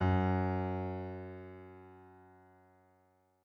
piano-sounds-dev
SoftPiano